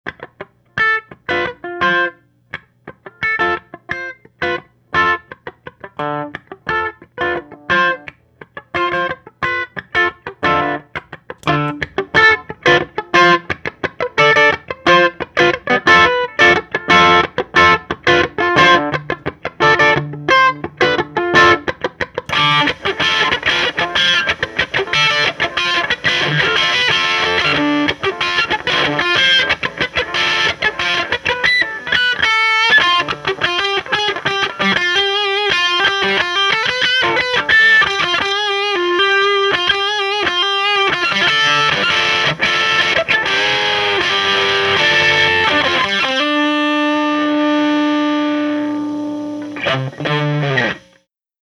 slight boost/nastyfuzz
these samples were recorded using either an ibanez rg560 loaded with duncan hot rails and jb junior pickups or a crappy strat knockoff with unknown pickups running a homebrew single ended amp (12ax7 and 6550) and 12" openback cabinet. miced with a 57.